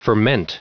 Prononciation du mot ferment en anglais (fichier audio)
Prononciation du mot : ferment